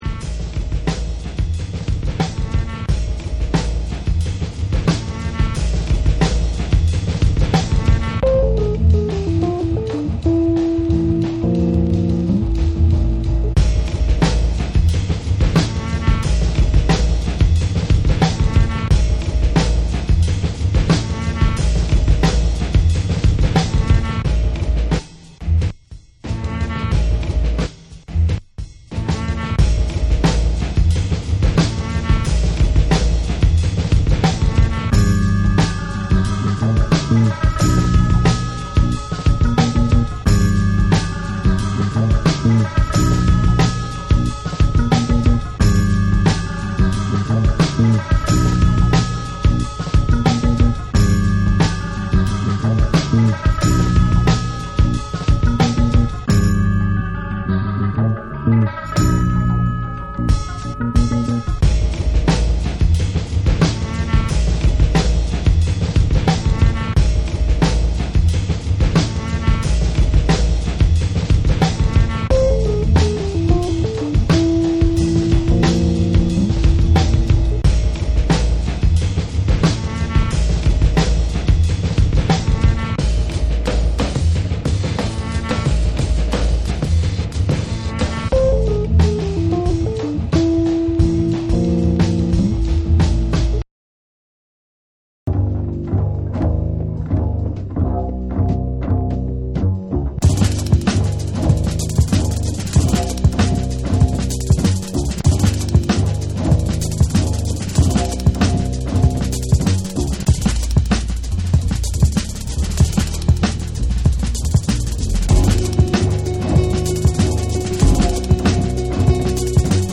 BREAKBEATS